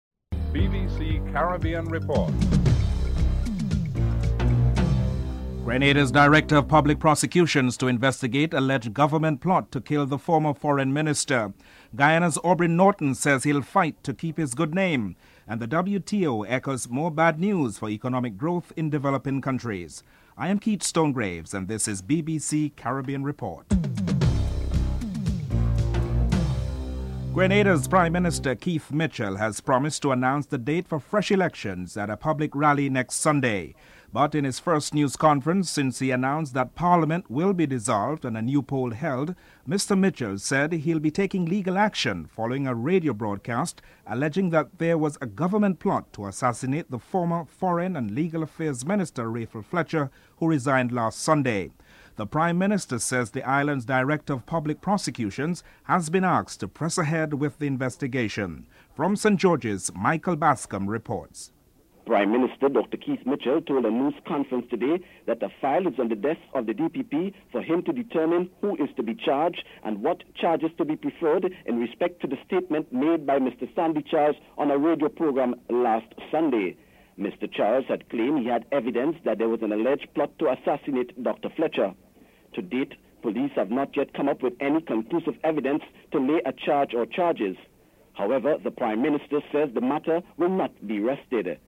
6. Europe's Chief Negotiator Sir Leon Brittan has appealed to the United States in the continuing war of words over trade in bananas. Sir Leon Brittan is interviewed (10:40-11:17)